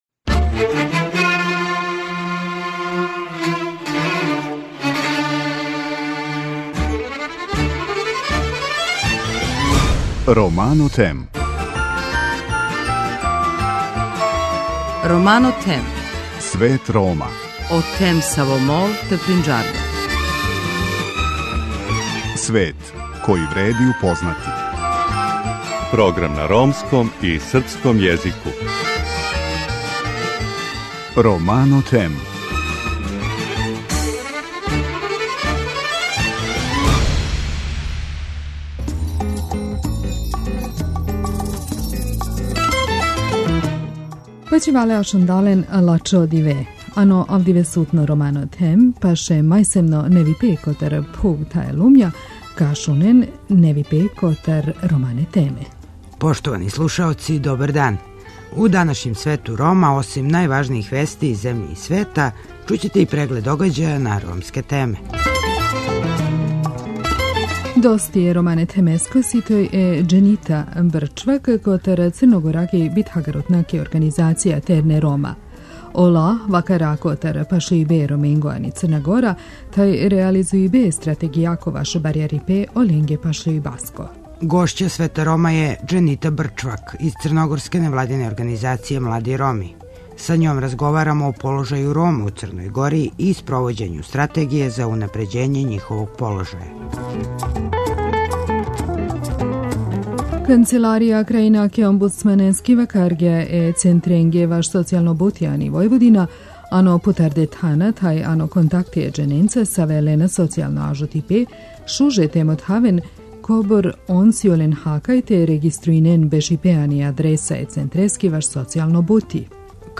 Са њом разговарамо о положају Рома у Црној Гори и спровођењу Стратегије за унапређење њиховог положаја.